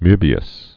(mœbē-əs, mā-, mō-)